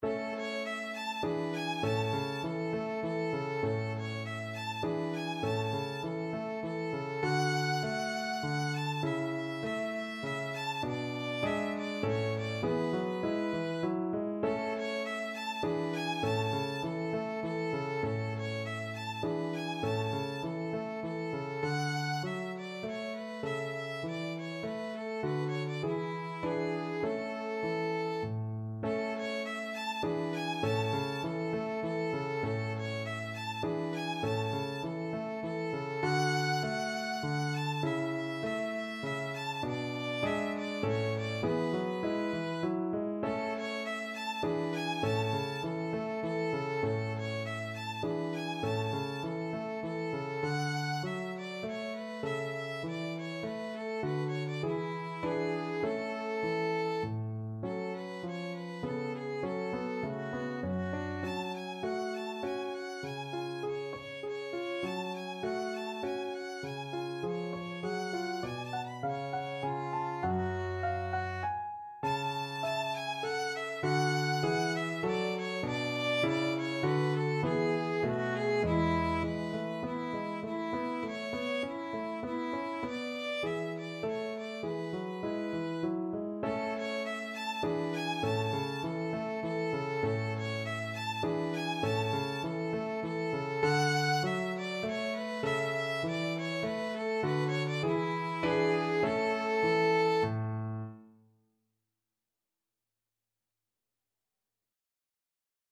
Moderato
3/4 (View more 3/4 Music)
Classical (View more Classical Violin Music)